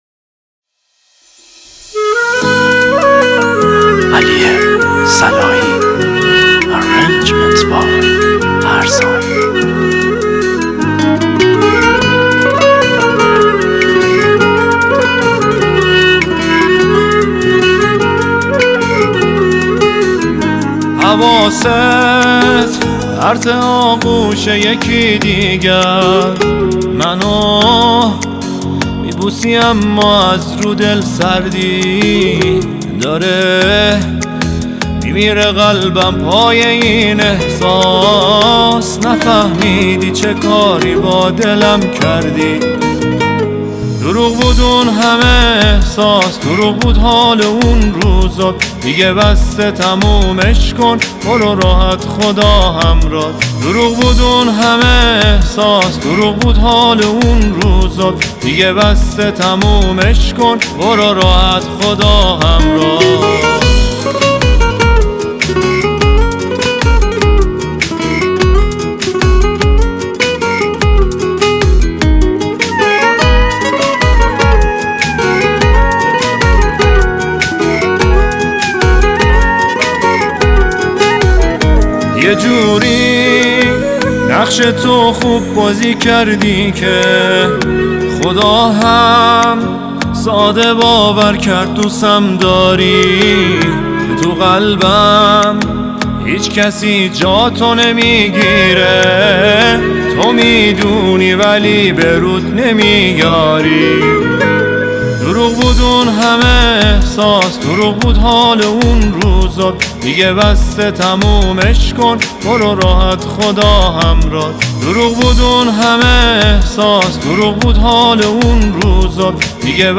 گیتار اسپانیش
کلارینت
گیتار باس